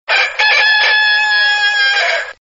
Rooster Sound Ringtone
• Animal Ringtones